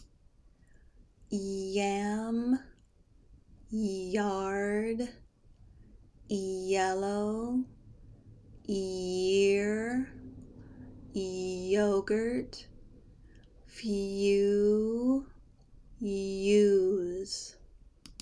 Pronounce Y in American English
As you say these words, notice that your tongue and teeth will be in position to make the long e sound, but your lips may begin to make the position they need for the vowel sound that follows y. A very slight “uh” sound will appear between y and the vowel that follows y. Listen and repeat these words with me:
Practice more words with Y